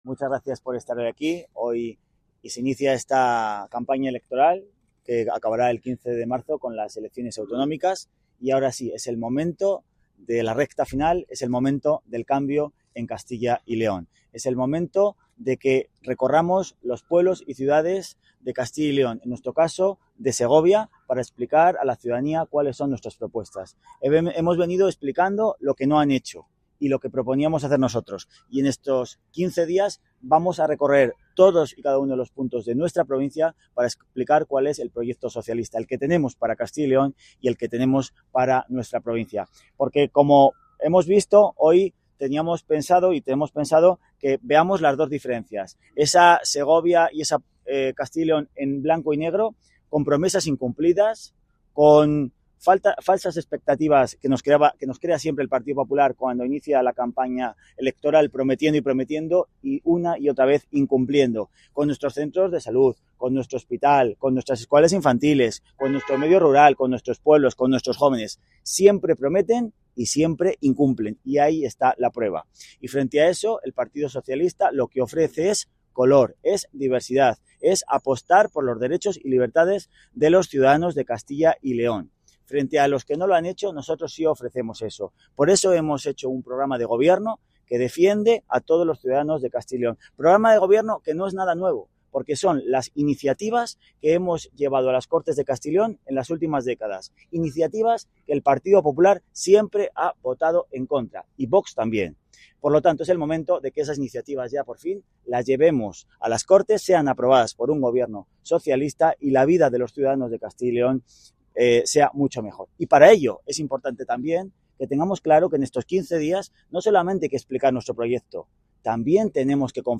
• El PSOE de Segovia ha celebrado su acto de inicio de campaña en el parque del reloj en Nueva Segovia para las elecciones autonómicas del 15 de marzo, reuniendo a la candidatura, militantes y simpatizantes en un encuentro marcado por la ilusión y la convicción de que la provincia necesita un cambio.